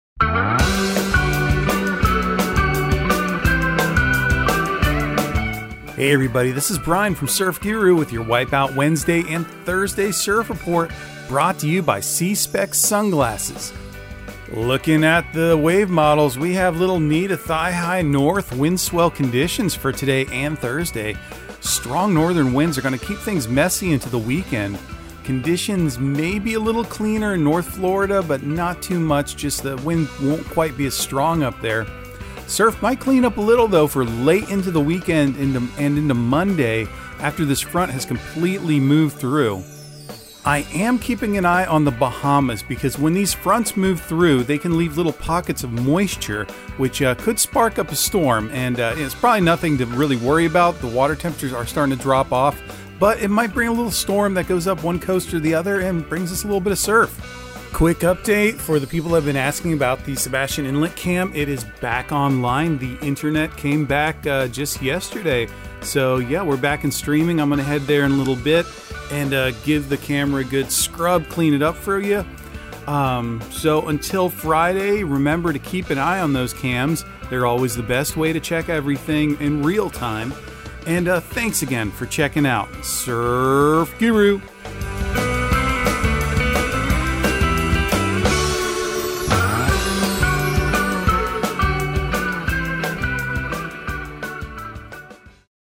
Surf Guru Surf Report and Forecast 10/19/2022 Audio surf report and surf forecast on October 19 for Central Florida and the Southeast.